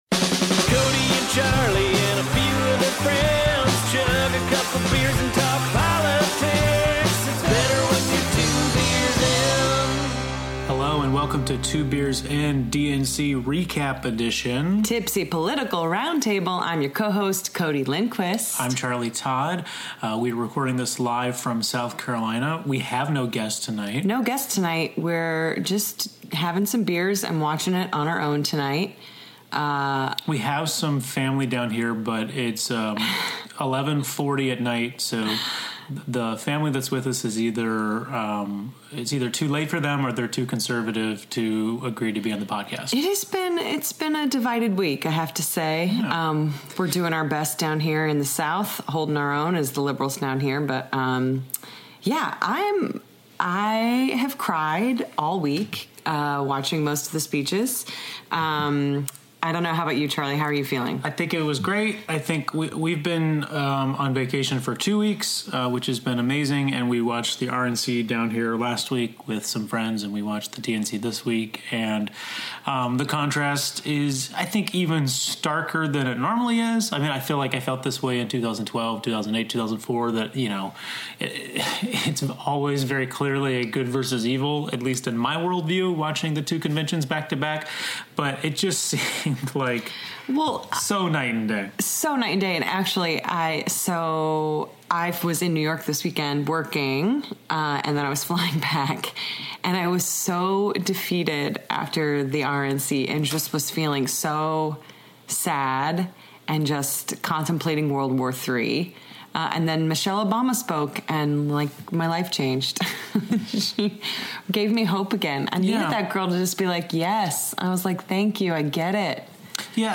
Two Beers In: A Tipsy Political Roundtable / DNC Recap!
Recorded immediately after Hillary's DNC speech and a few beers, we recap the week.